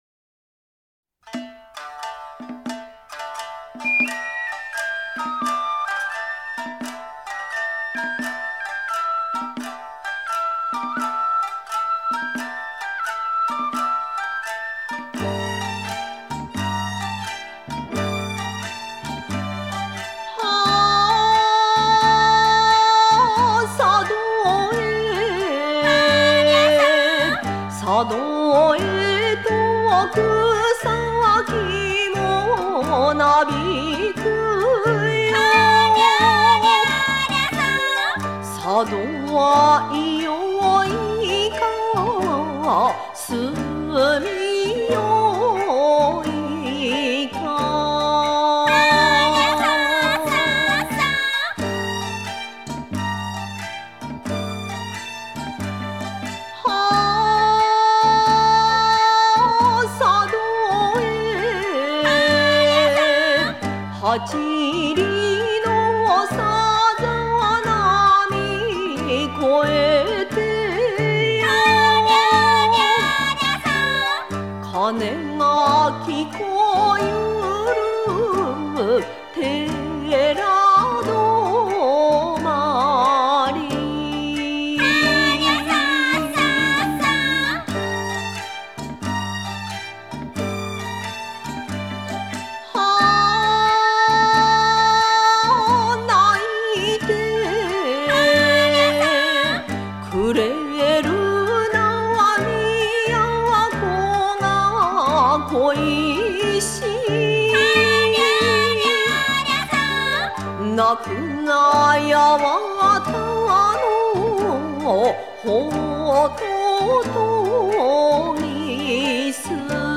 Sado okesa est une chanson de l’île Sado
L’île est non seulement archi-célèbre pour sa chanson folklorique Sado Okesa, mais aussi pour ses “Tambours du Diable” (Onidaiko) qui sont une espèce de danse du lion (danse qui à l’origine avait pour but de chasser les esprits malfaisants des maisons en les terrorisant par l’aspect formidable et la gesticulation des masques de lion) et d’autres arts traditionnels de la scène.